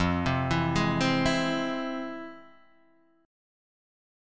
F Minor Major 7th